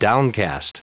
Prononciation du mot downcast en anglais (fichier audio)
Prononciation du mot : downcast